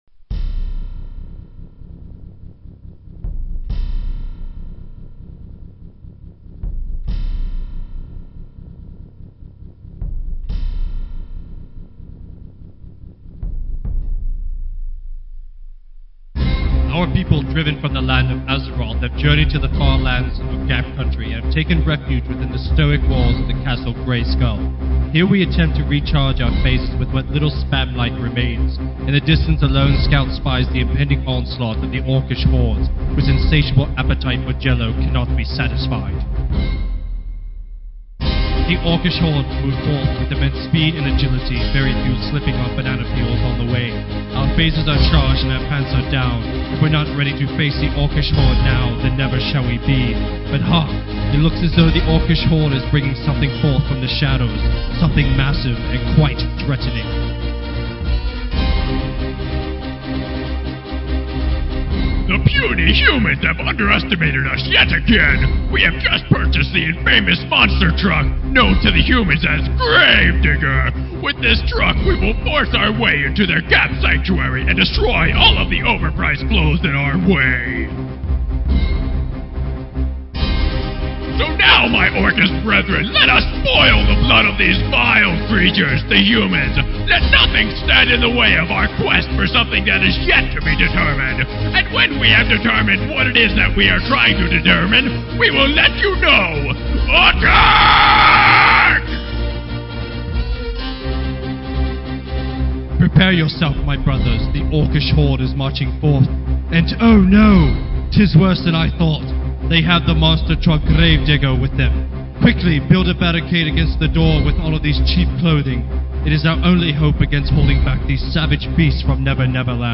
No bother, we've got a sound clip direct from one of the Warcraft IV movies scenes! Listen as the puny little humans run from the mighty Orcish Horde and Gravedigger!
Also, here is the script from the scene in the above MP3 so you can read along with it as you listen to the glorious battle scene!